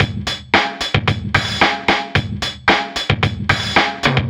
Track 15 - Drum Break 04.wav